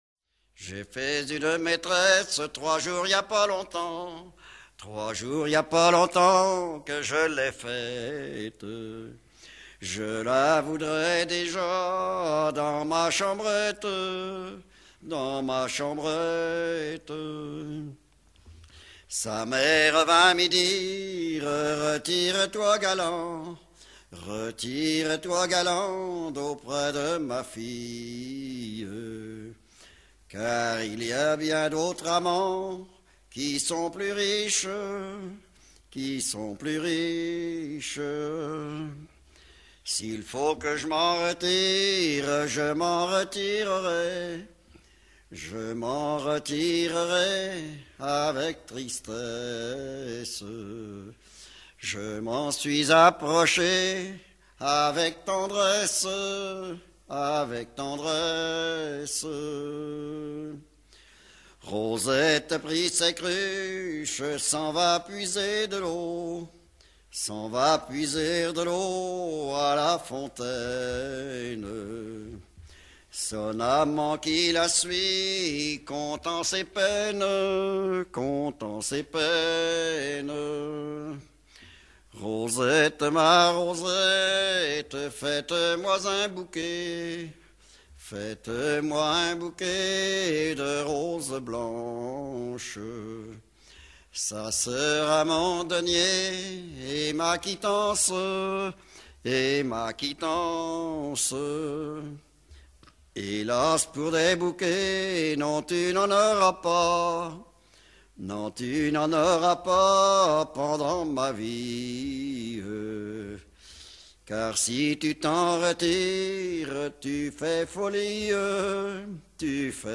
Genre strophique
Pièce musicale éditée